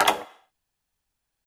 MH - Menu Click 1 (MH3U).wav